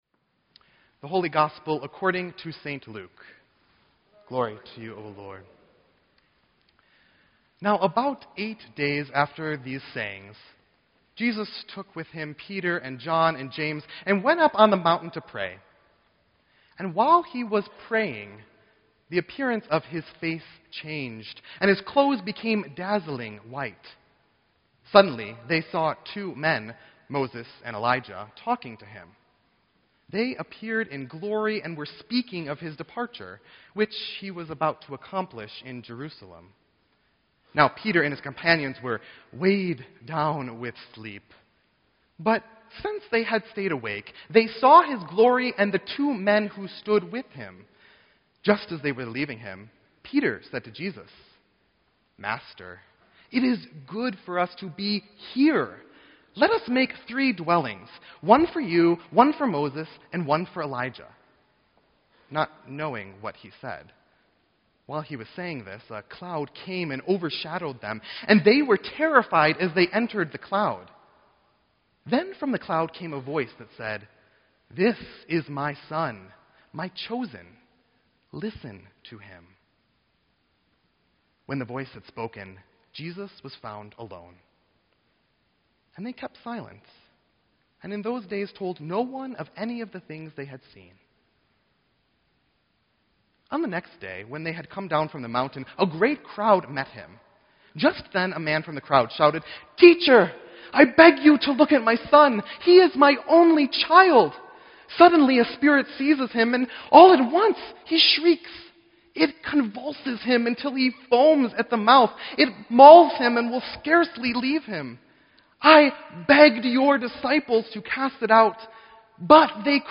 Sermon_2_7_16-2.mp3